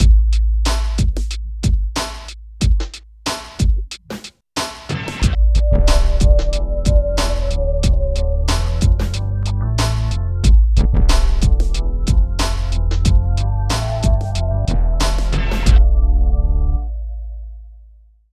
23 LOOP   -L.wav